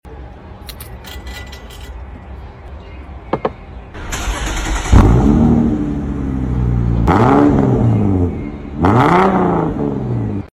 🚗✨ TURBO SOUND sound effects free download
🚗✨ TURBO SOUND WHISTLE MUFFLER EXHAUST